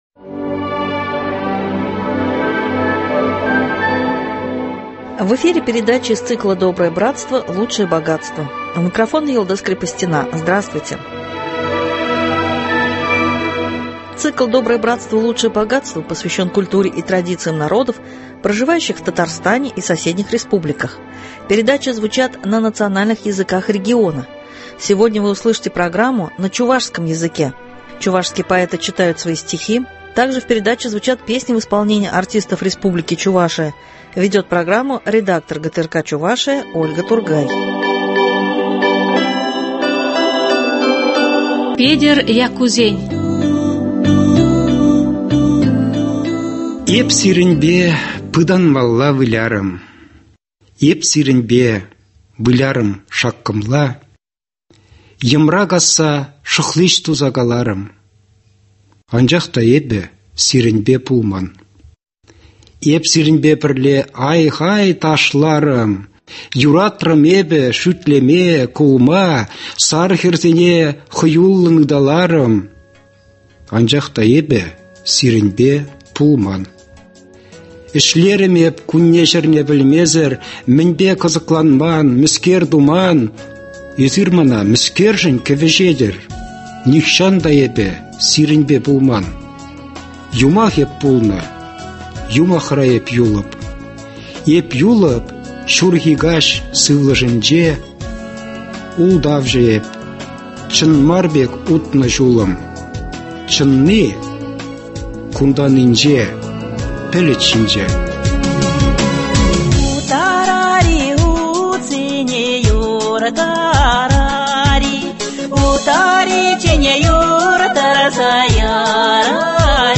звучат стихи чувашских писателей.